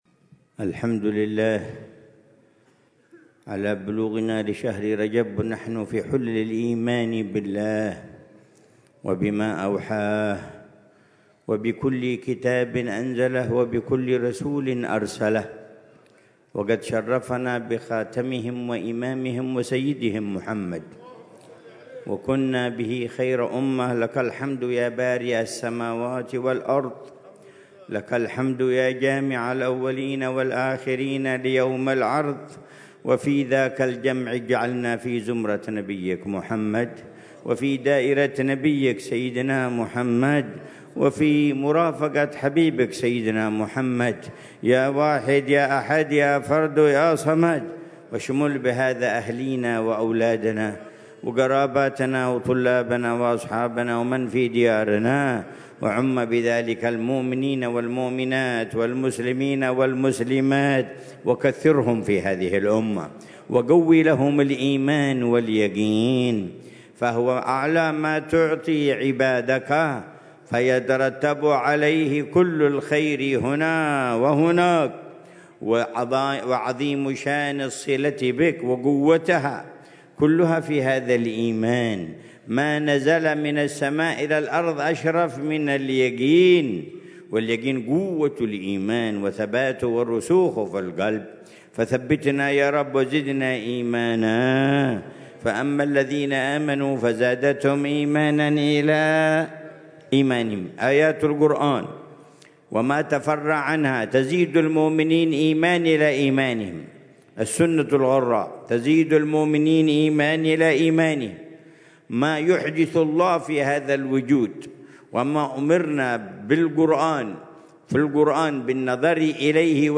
محاضرة العلامة الحبيب عمر بن محمد بن حفيظ ضمن سلسلة إرشادات السلوك في دار المصطفى، ليلة الجمعة 3 رجب الأصب 1446هـ، بعنوان: